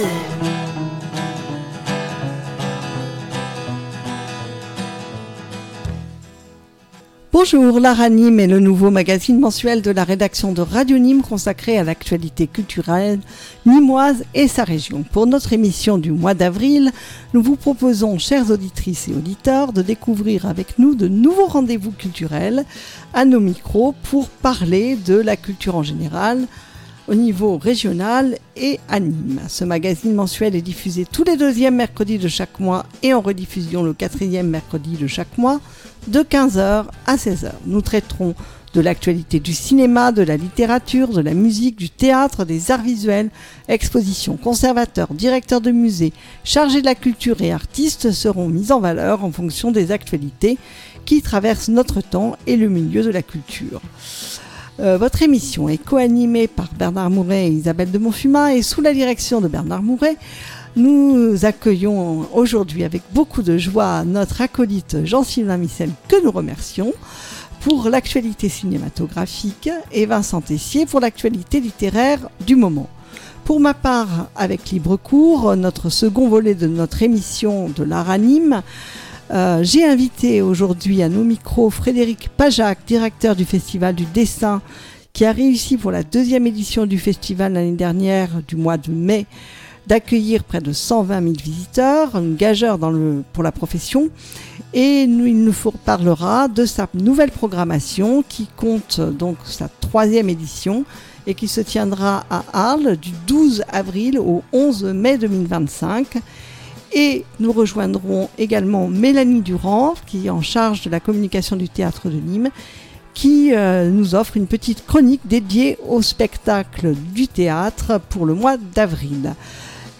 Émission mensuelle « L’Art à Nîmes » - Mercredi 12 Mars 2025 de 15h00 à 16h00. Coup de Projecteur sur le Festival du Dessin d’Arles.